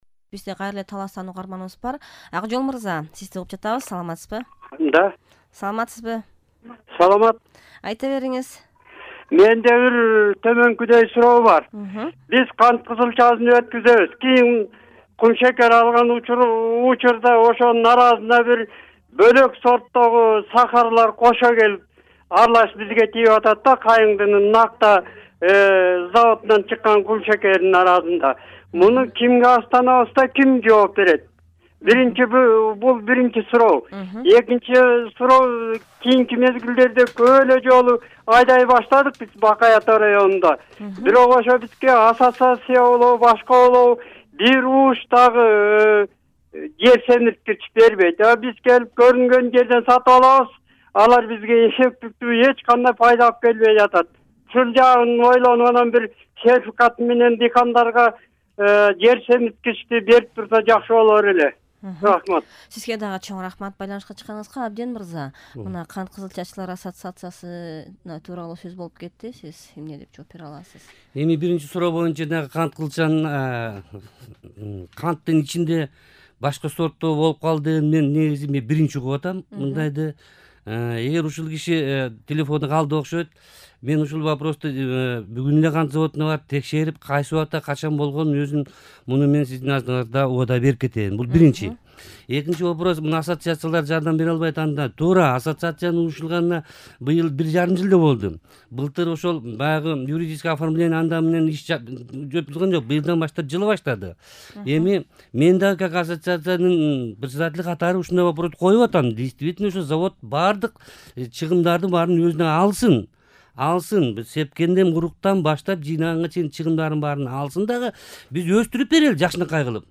Ыстаканда кантың бар...бы? (талкуунун биринчи бөлүгүн ушул жерден угуңуз)